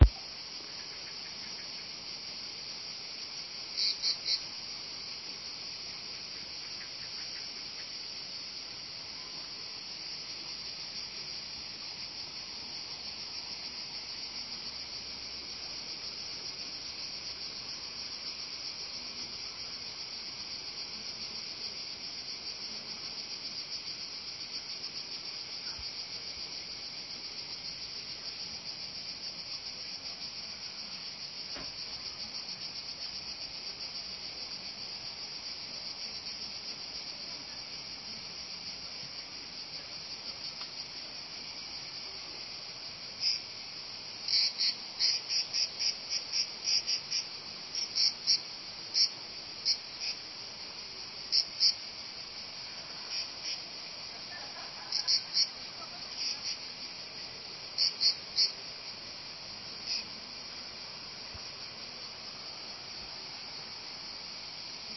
Sounds of nature
Cicadas on Brac, Croatia
cicadas.mp3